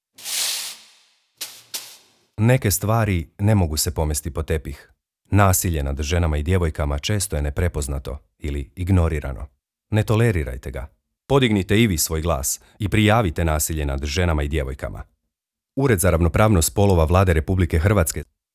Ured za ravnopravnost spolova je povodom obilježavanja Međunarodnog dana borbe protiv nasilja nad ženama organizirao medijsku objavu radijskog jingle-a na radijskim postajama HR mreže, Hrvatskog radija 1. i 2. program te programa Radio Sljeme.
radio reklama Prašina 2018.wav